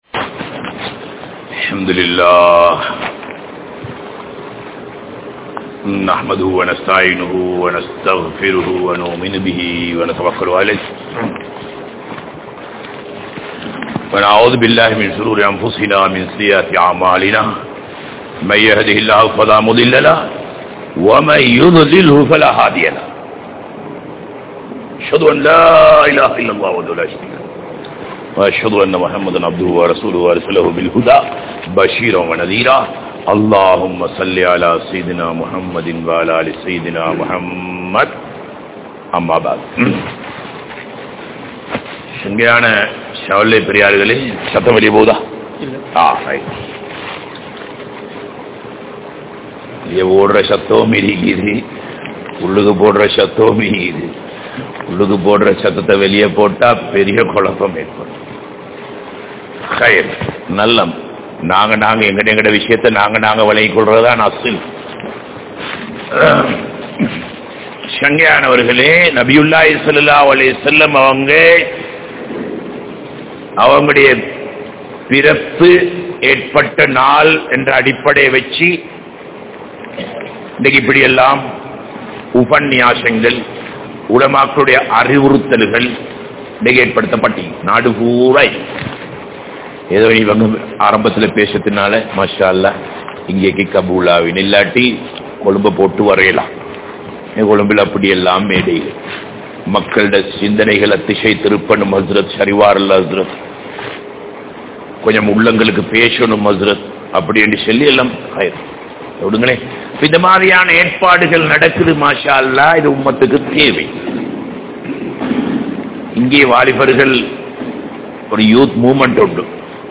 Intha Ummaththin Poruppuhal (இந்த உம்மத்தின் பொறுப்புகள்) | Audio Bayans | All Ceylon Muslim Youth Community | Addalaichenai
Al-Hiqma Jumua Masjith